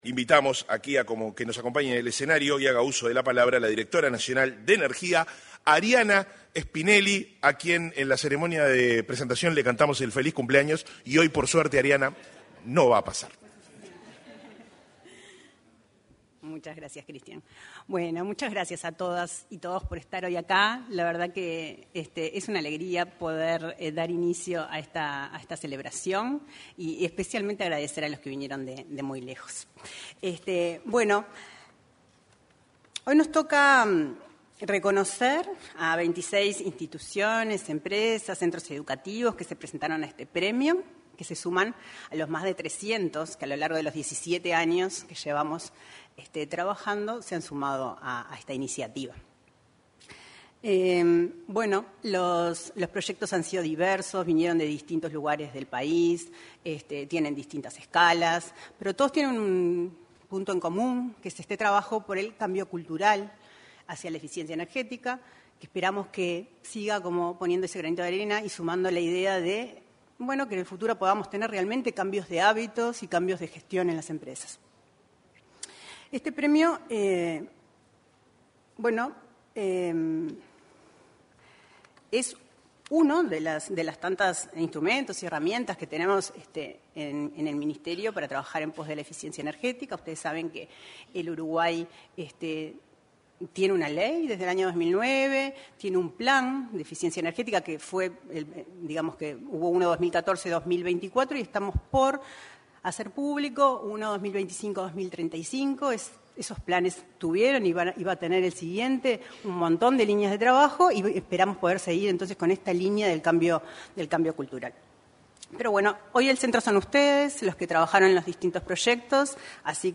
Ceremonia de Entrega del Premio Nacional de Eficiencia Energética 28/11/2025 Compartir Facebook X Copiar enlace WhatsApp LinkedIn Este viernes 28 se realizó, en el auditorio del edificio anexo de Torre Ejecutiva, la ceremonia de entrega de reconocimientos del Premio Nacional de Eficiencia Energética 2025, que distingue las iniciativas que demostraron avances concretos en el uso responsable y eficiente de la energía en todo el país. En la ceremonia, expresaron sus valoraciones la ministra de Industria, Energía y Minería, Fernanda Cardona, y la directora nacional de Energía, Arianna Spinelli.